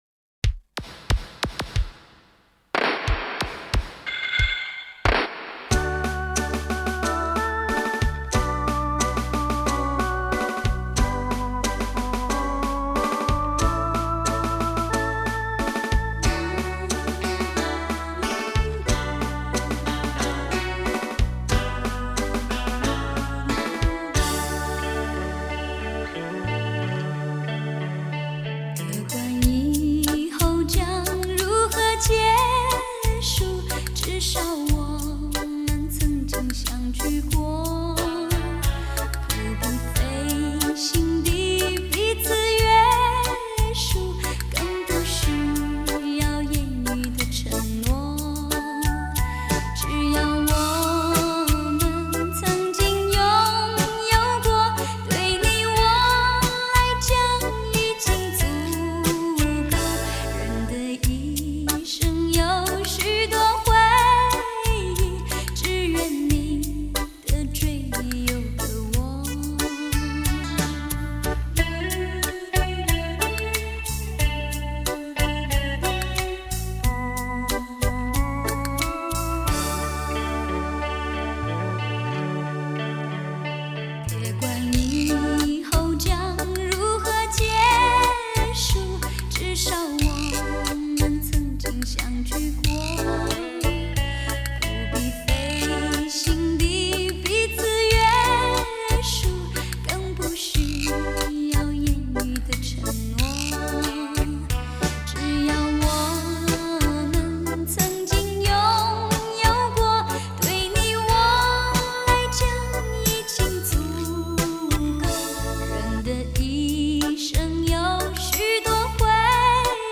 高保真数位360度立体环绕音效测试天碟